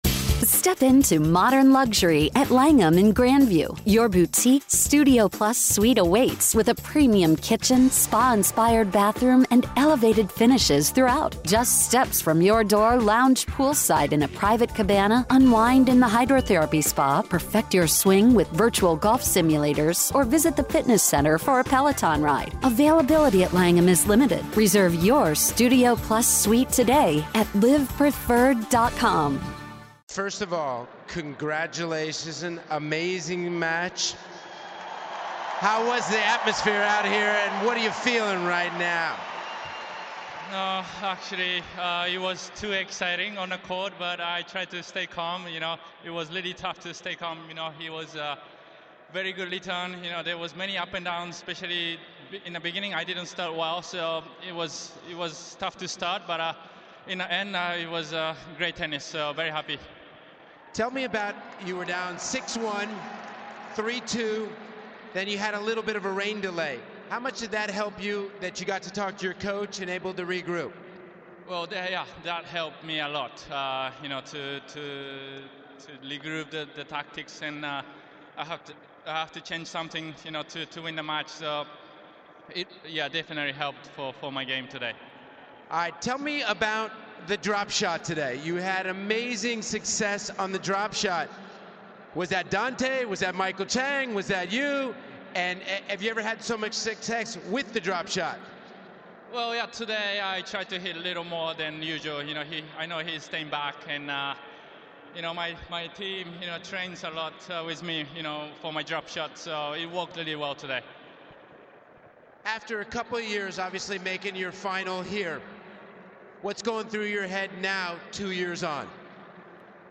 Nishikori interview